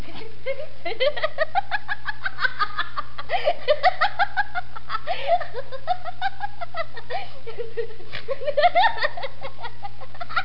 Laughing Sound Effect
Download a high-quality laughing sound effect.
laughing.mp3